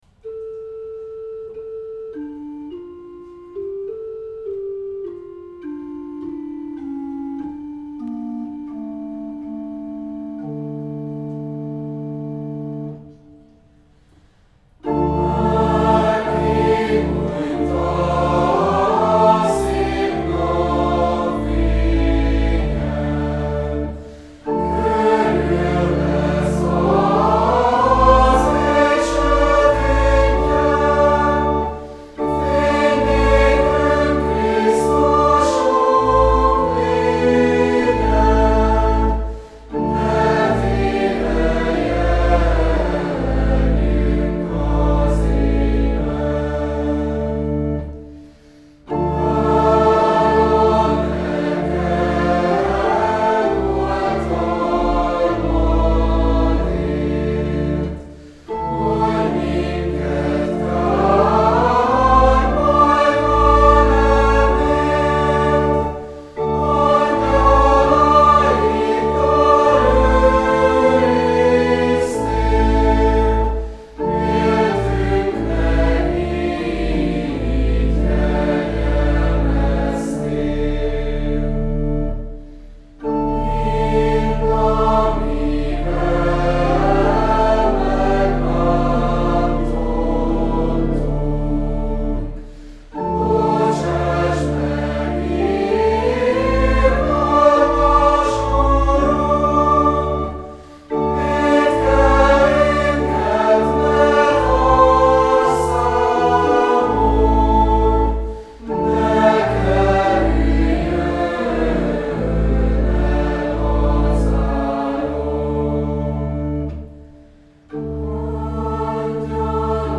E kifejező dór dallam elején a lehajló kvint jelképezheti a naplementét. A négysoros strófa összetartó erejét a dallamsorok kezdetének és végének összecsengése, a kvint-oktáv-alaphang használata adja.